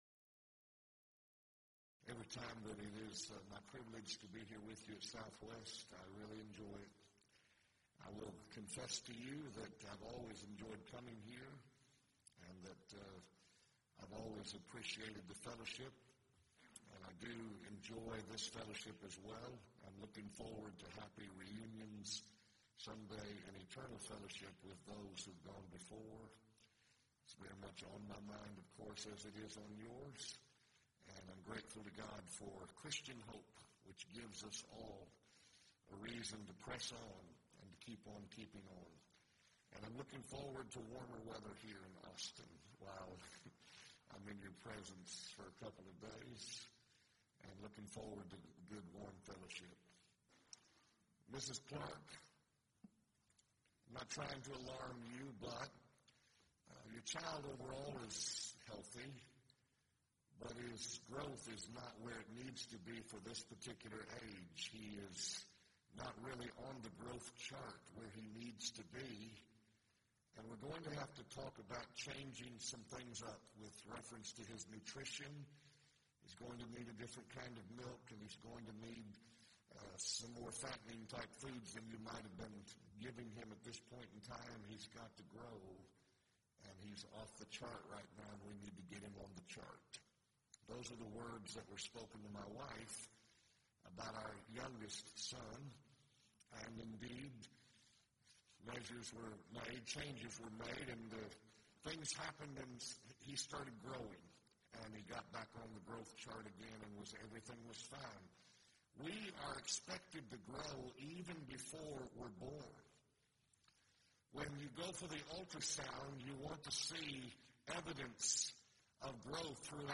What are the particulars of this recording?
Event: 2nd Annual Arise Workshop